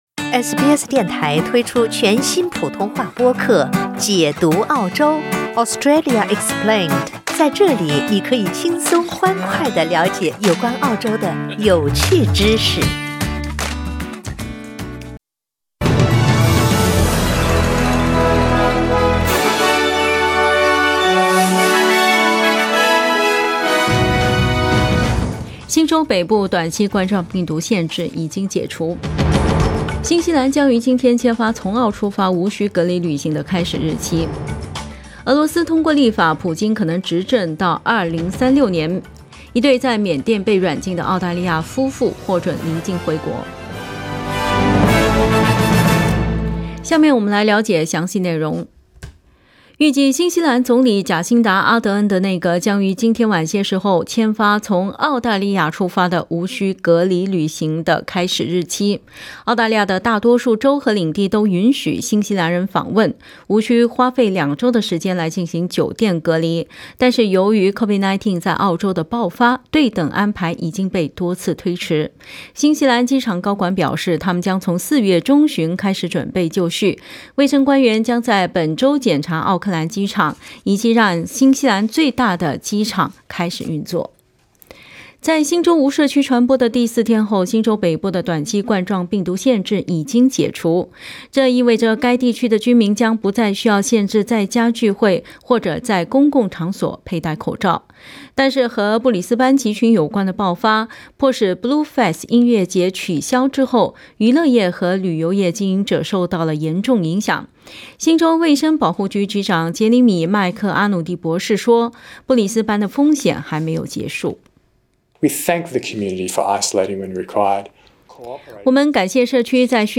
SBS早新聞 （4月6日）